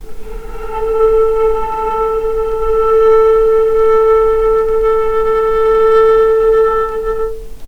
vc-A4-pp.AIF